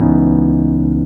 EP CHORD-L.wav